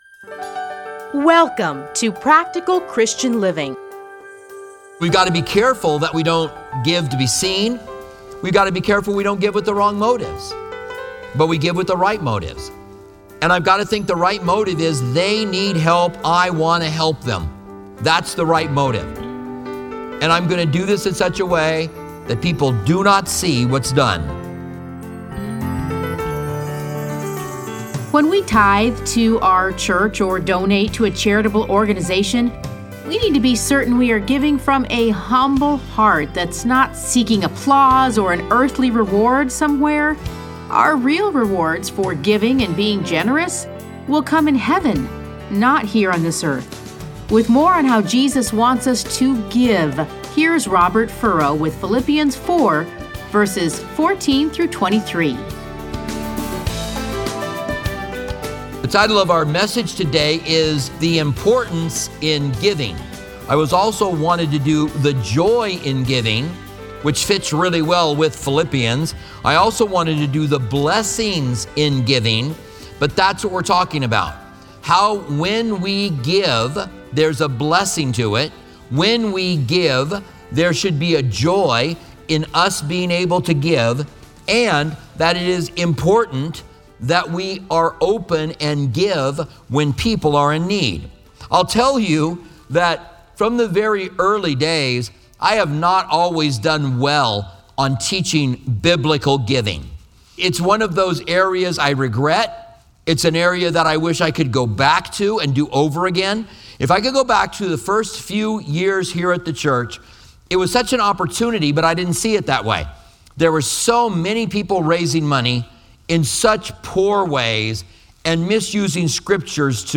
Listen to a teaching from A Study in Philippians 4:14-23.